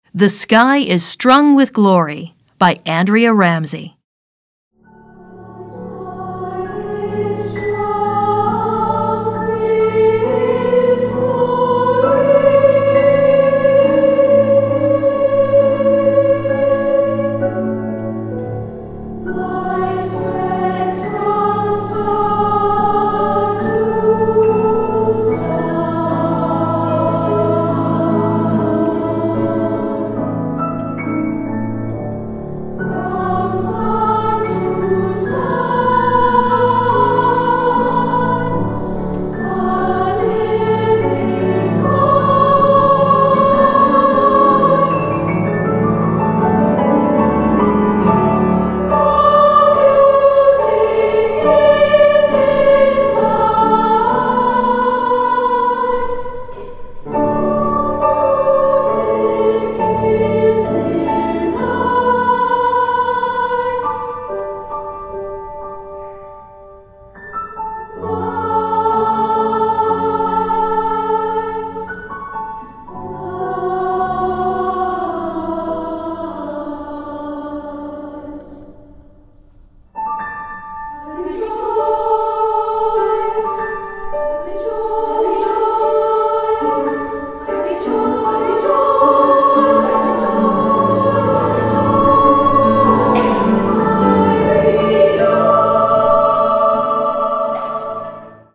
Voicing: SA divisi